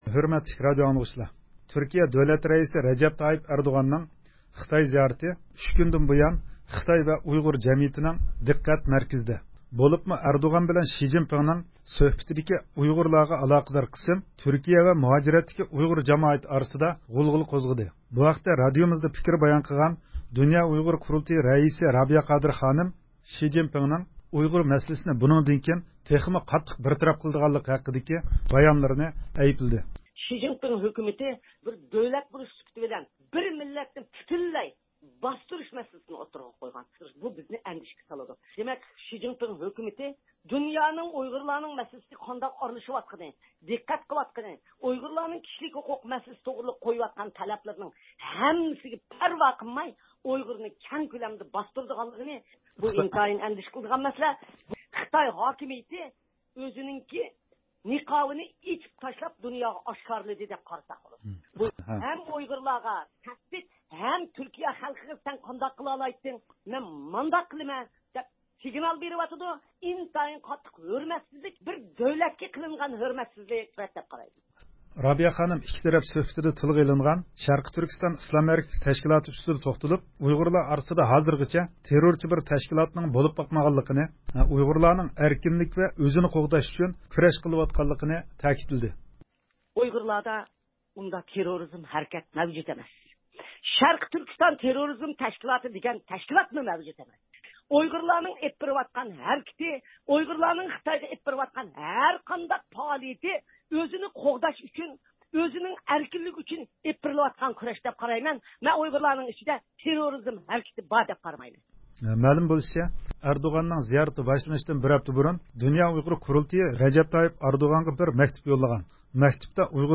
دۇنيا ئۇيغۇر قۇرۇلتىيى رەئىسى رابىيە قادىر خانىم بۈگۈن رادىيومىز زىيارىتىنى قوبۇل قىلىپ، تۈركىيە دۆلەت رەئىسى رەجەپ تايىپ ئەردوغاننىڭ خىتاي زىيارىتى ھەققىدە پىكىر بايان قىلدى.